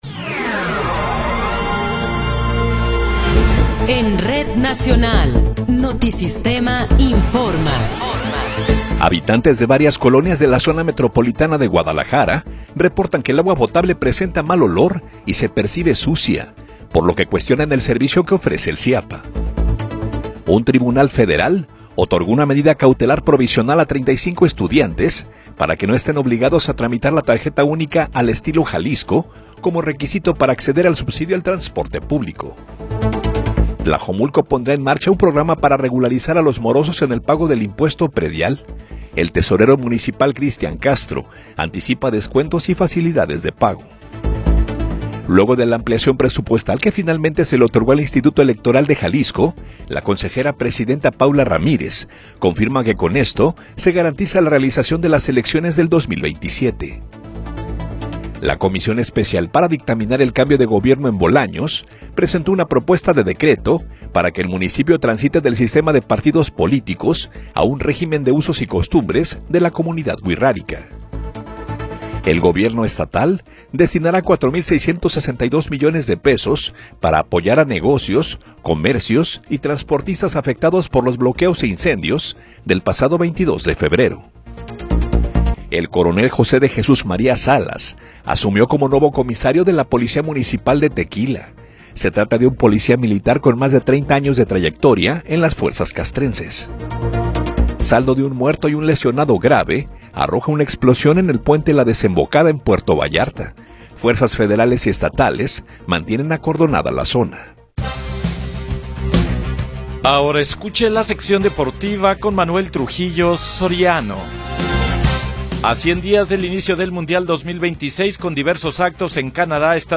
Noticiero 21 hrs. – 3 de Marzo de 2026